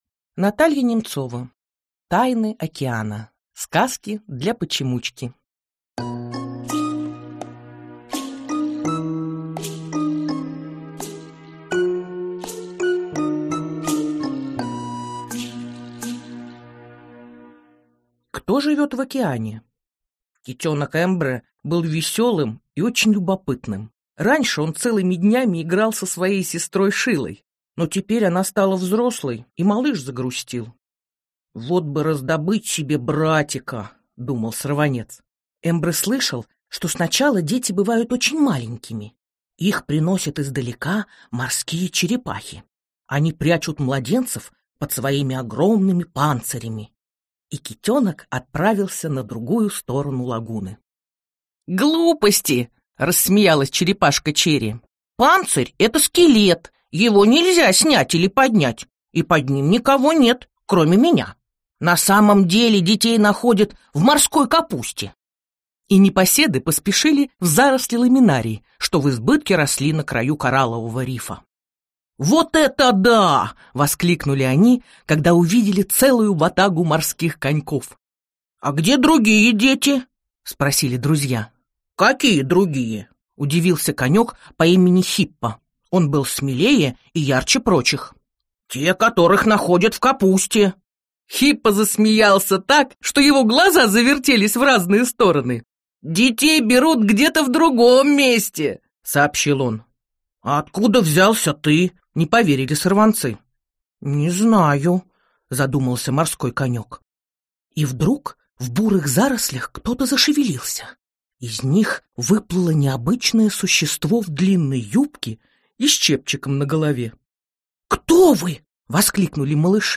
Аудиокнига Тайны океана. Сказки для почемучки | Библиотека аудиокниг